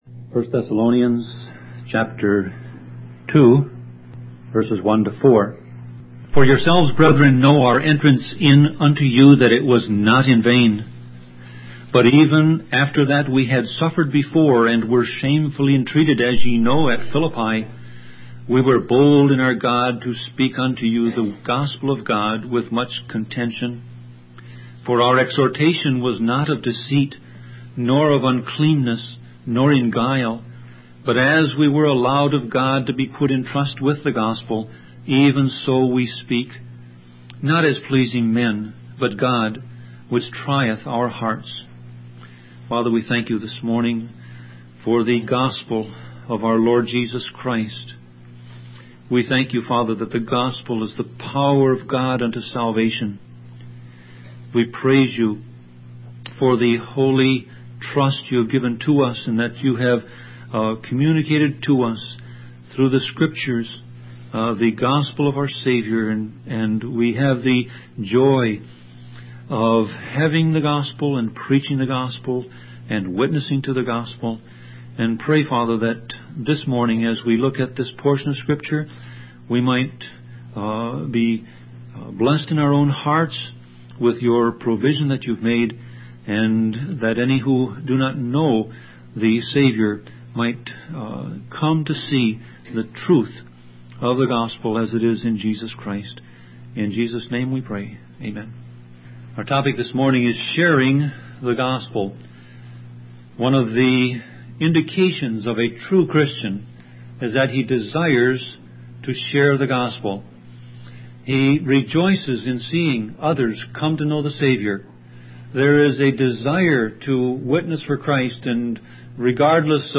Sermon Audio Passage: 1 Thessalonians 2:1-4 Service Type